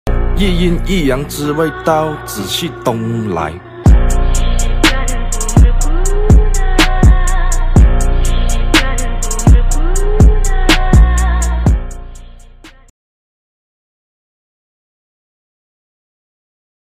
Unboxed the Epomaker P65 in all black 🖤 and wow… I’m in love. 🥹 Typing on this is like butter, deep, creamy thocks that make you feel every keypress.